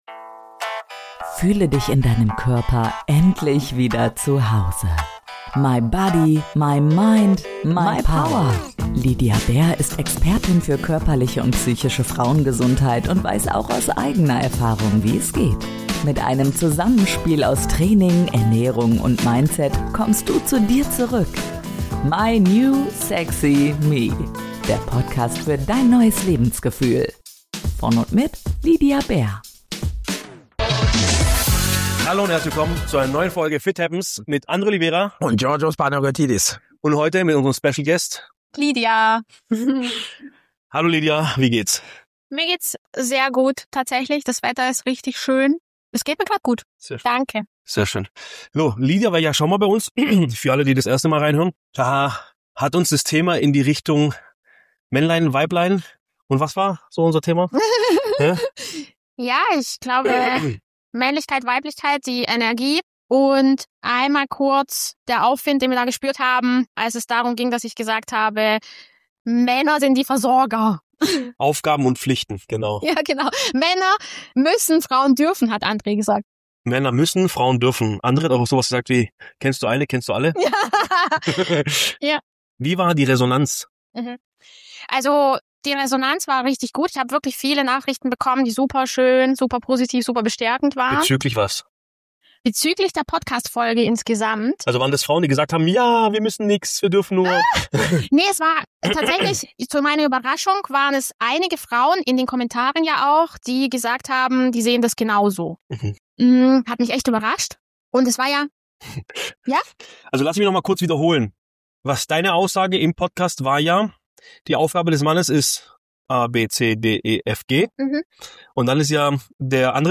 In dieser Podcast-Folge treffen unterschiedliche Sichtweisen aufeinander. Es geht um das Zusammenspiel von weiblicher und männlicher Energie, um Beziehung im echten Alltag und um die Frage, warum wir oft mehr im Tun sind, als wir eigentlich wollen.